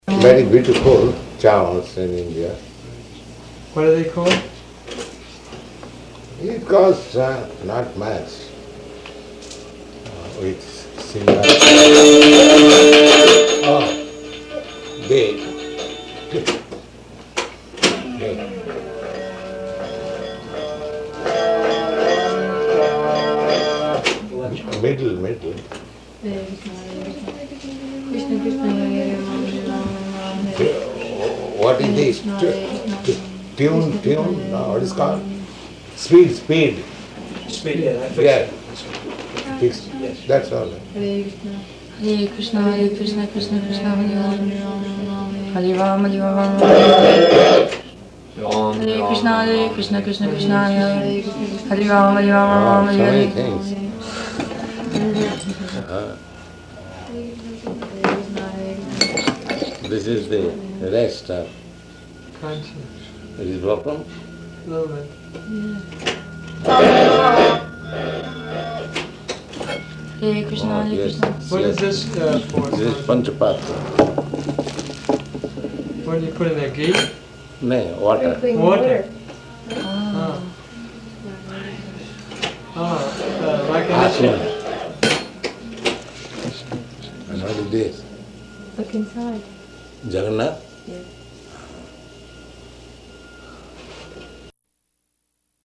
Room Conversation
Location: Boston